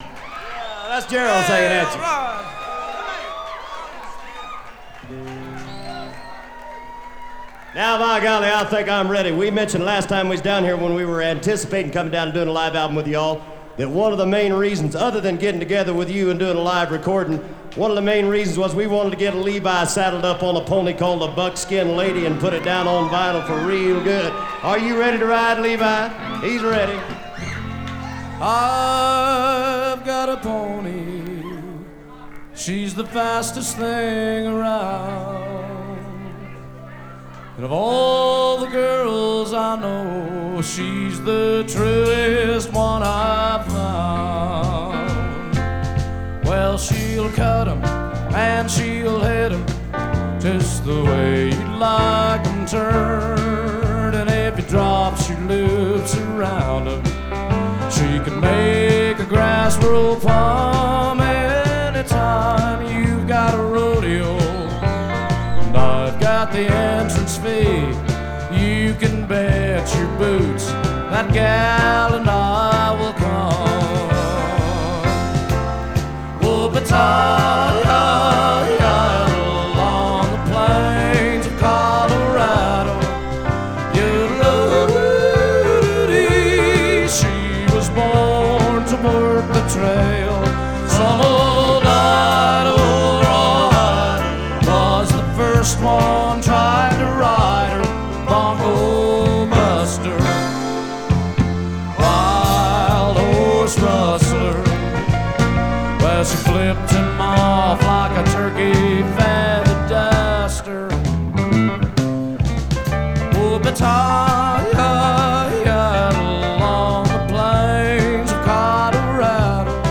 live album